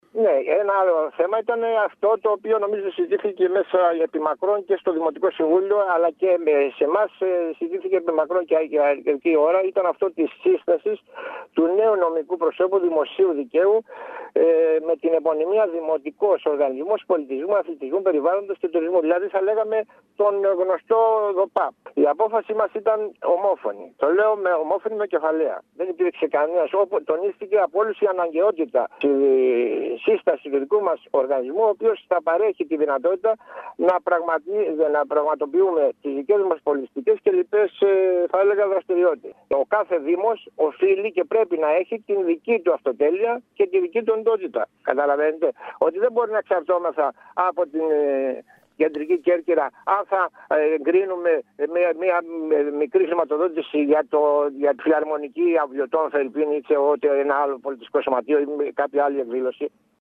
Παράλληλα την ίδια ώρα – χθες το βράδυ – το ίδιο θέμα συζητήθηκε και στο Δημοτικό συμβούλιο Βόρειας Κέρκυρας, το οποίο έλαβε ομόφωνη απόφαση να δημιουργήσει ανεξάρτητο Οργανισμό Πολιτισμού – Αθλητισμού – Τουρισμού. Ακούμε τον πρόεδρο του ΔΣ Βόρειας Κέρκυρας Κώστα Γρηγορόπουλο.